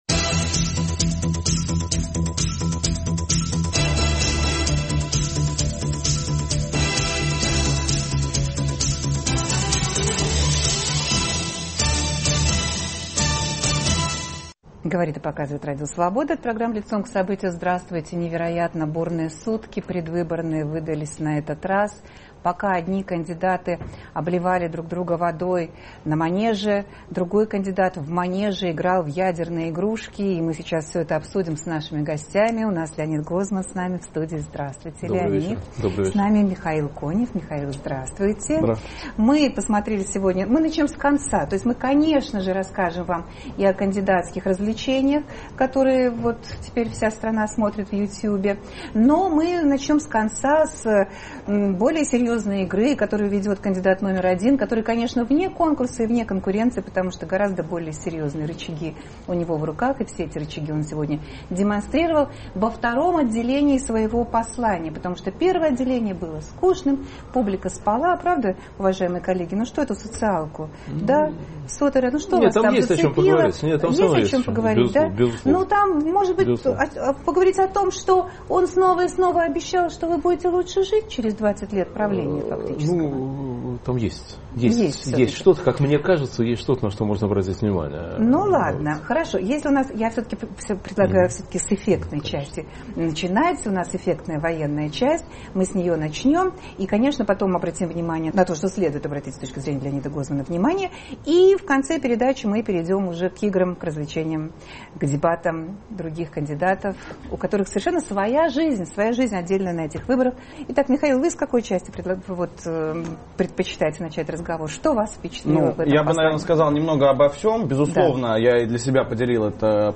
Владимир Путин, выступая перед Федеральным Собранием, вдруг затряс новейшим ядерным вооружением, которому нет никаких преград. Кого он хочет напугать - чужих или своих? Обсуждают политики и аналитики